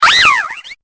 Cri de Mesmérella dans Pokémon Épée et Bouclier.